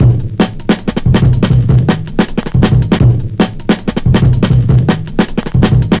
Index of /90_sSampleCDs/Zero-G - Total Drum Bass/Drumloops - 1/track 10 (160bpm)